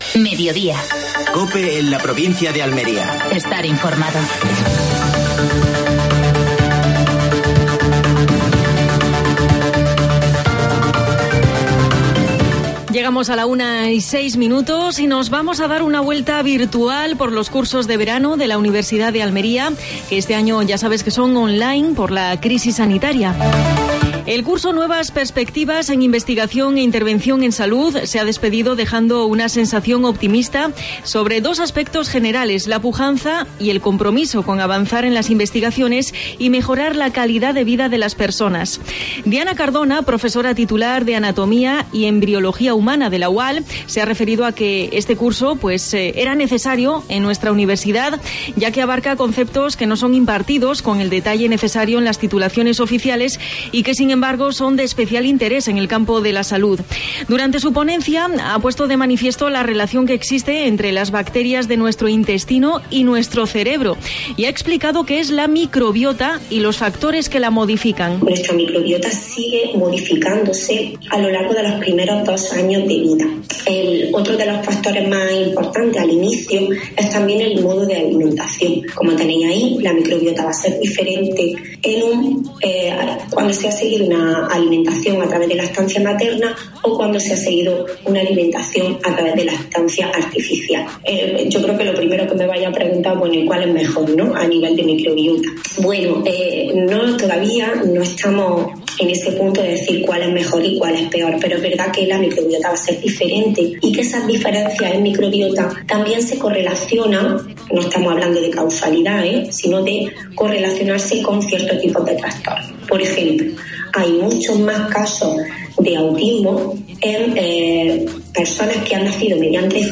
AUDIO: Actualidad en Almería. Entrevista a Fernando Giménez (diputado de Turismo de la Diputación Provincial de Almería). Última hora deportiva.